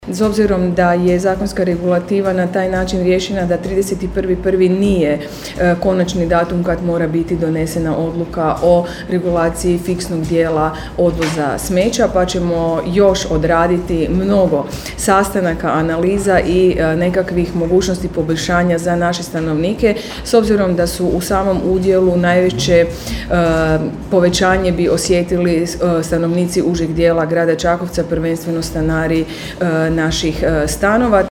Prijedlog je stigao nakon brojnih reakcija građana kroz e-savjetovanje, najavila je gradonačelnica na konferenciji za medije prije sjednice Gradskog vijeća: